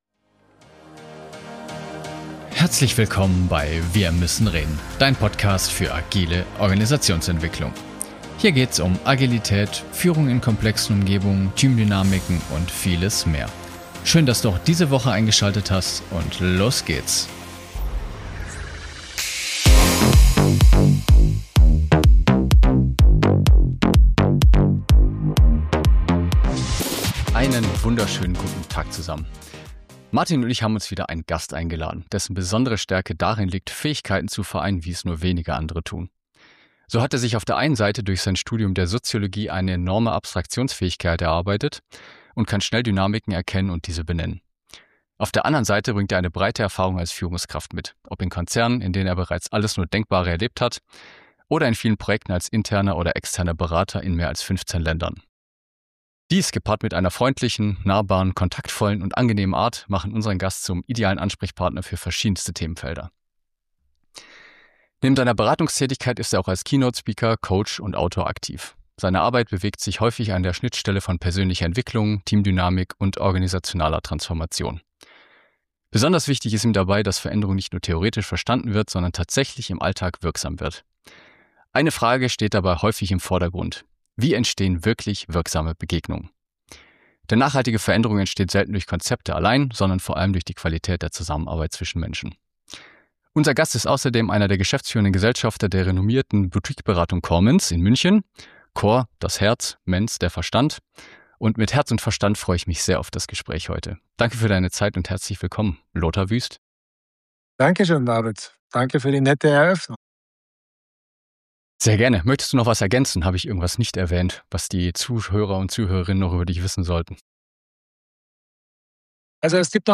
In diesem Gespräch